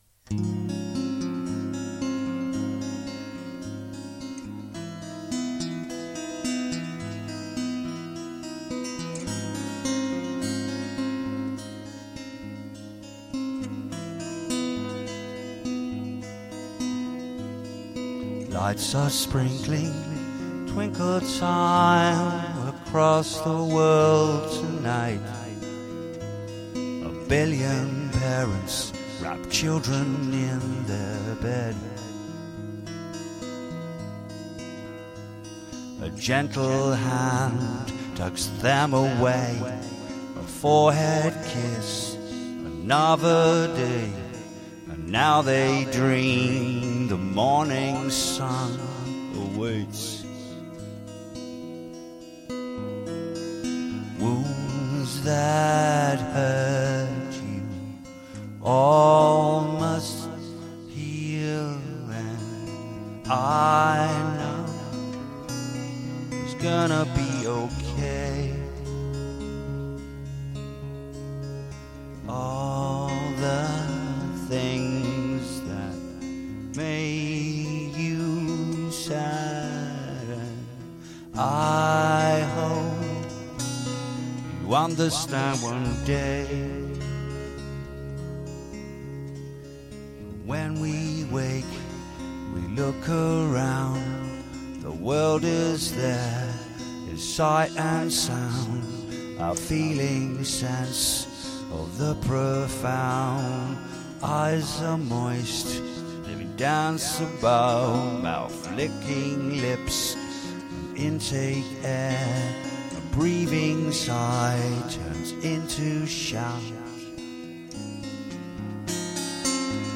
wounds_must_heal_acoustic_demo.mp3